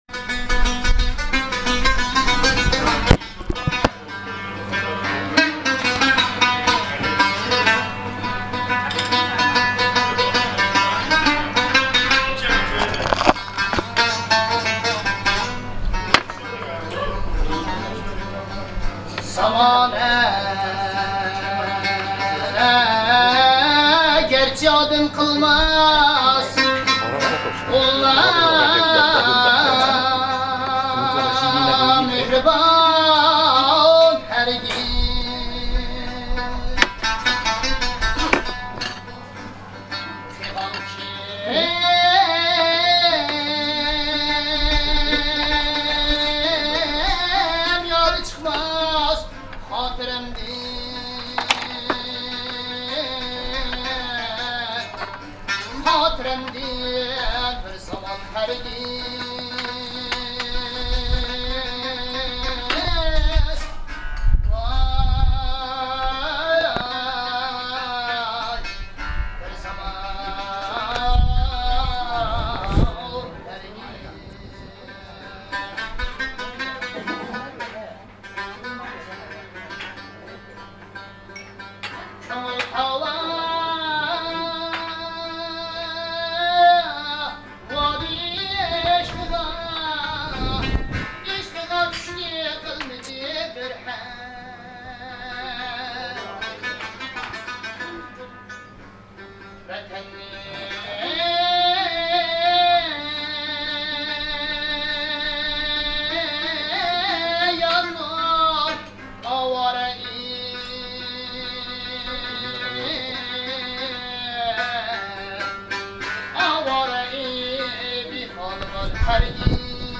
ホテル近くのレストランで民族音楽を聞きながら
彼は有名な奏者だという